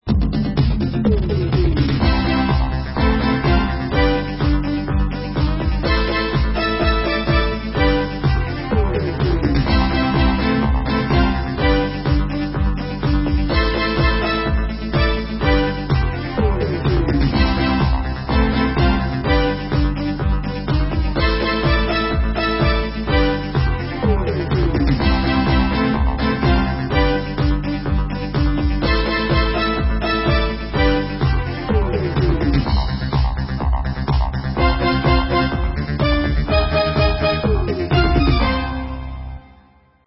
• Жанр: Танцевальная
Europop / Synth-pop. 2003.